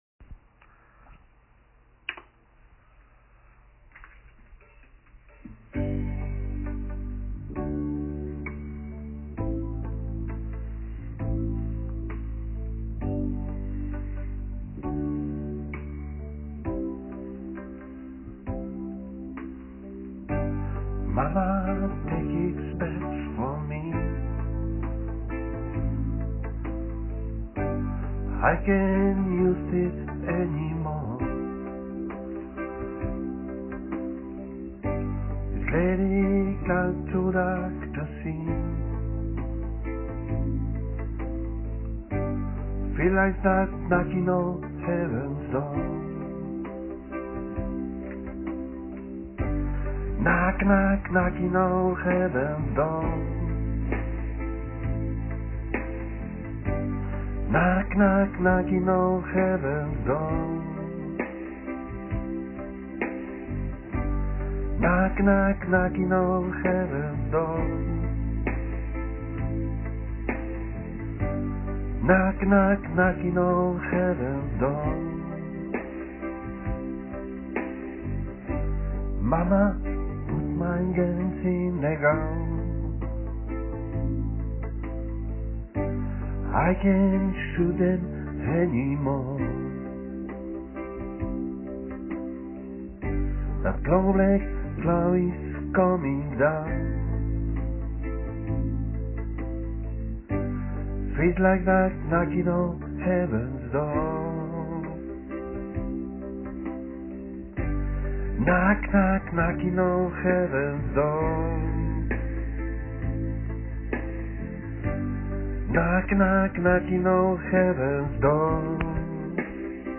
clasical gutiar vocal
clasical guitar